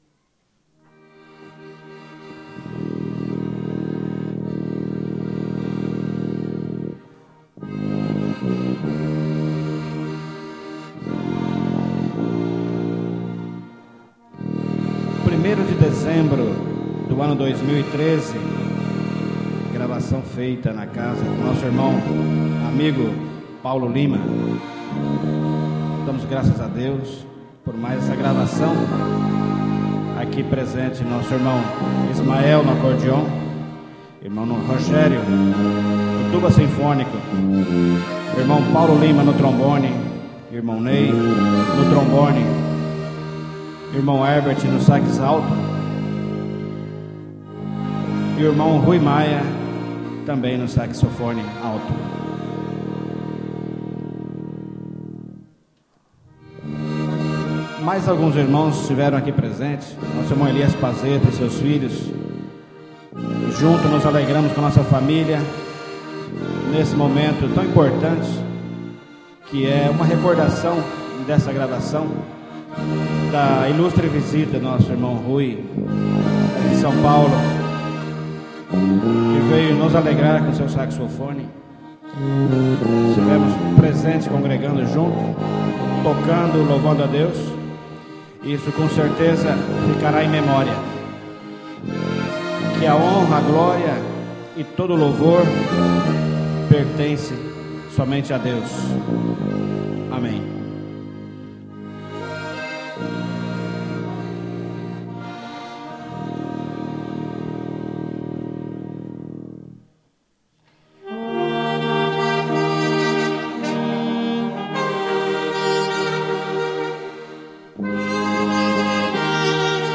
sax1
trombone1
acordeon
tuba-sinfonica